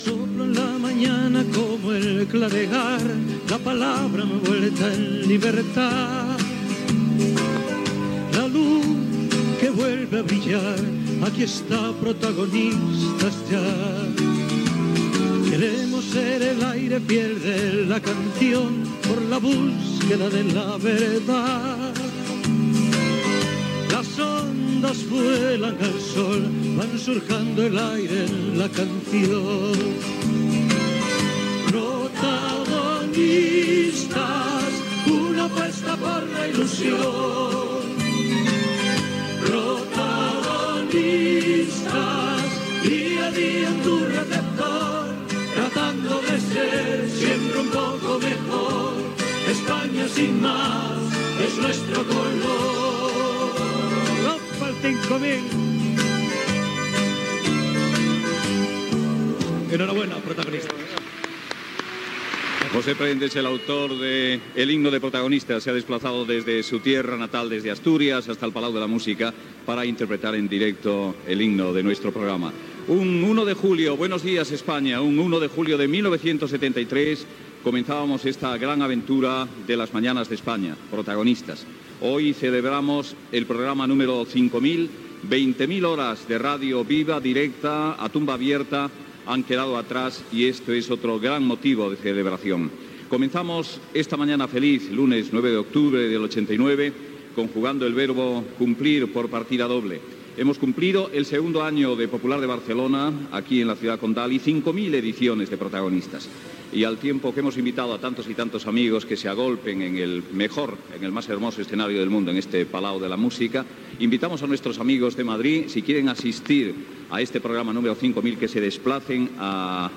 Resum de l'especial 5000 programes, fet al Palau de la Música. 20.000 hores de ràdio. Cançó del programa. Intervenció de Doña Rogelia, la cantant Marina Rossell, apunt humorístic de Tip, opinió de Fernando Ónega sobre el programa
Info-entreteniment
FM